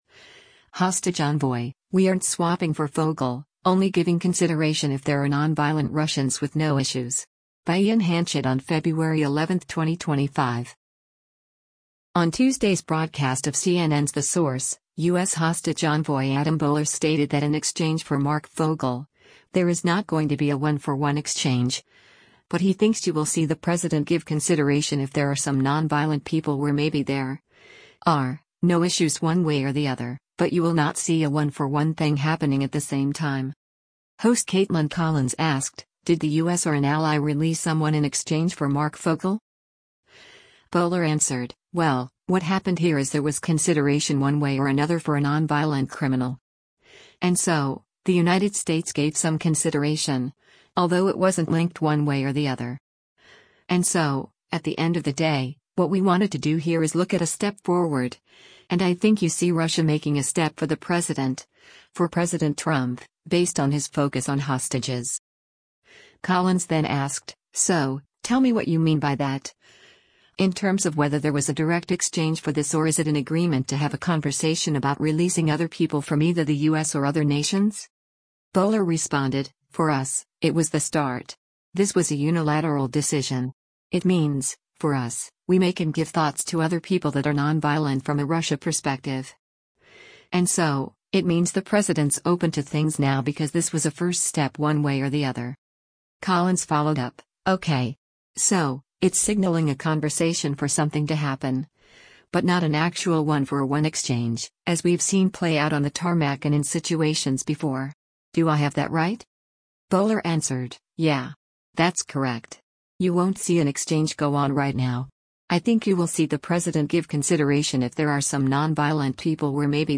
On Tuesday’s broadcast of CNN’s “The Source,” U.S. Hostage Envoy Adam Boehler stated that in exchange for Marc Fogel, there is not going to be a one-for-one exchange, but he thinks “you will see the President give consideration if there are some nonviolent people where maybe there [are] no issues one way or the other, but you will not see a one-for-one thing happening at the same time.”
Host Kaitlan Collins asked, “Did the U.S. or an ally release someone in exchange for Marc Fogel?”